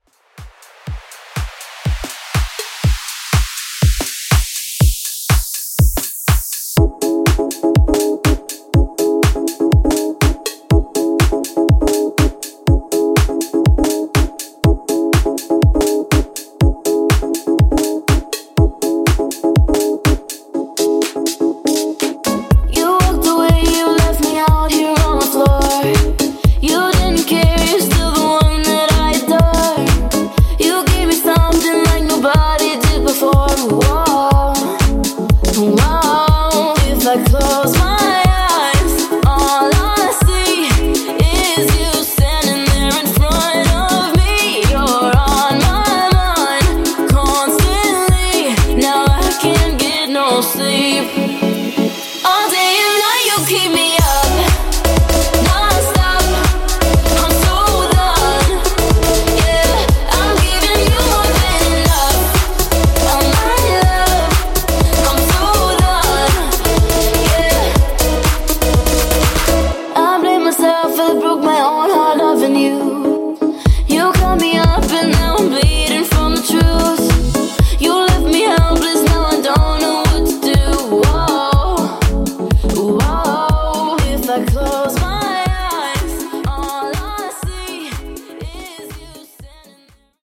Club Extended Mix)Date Added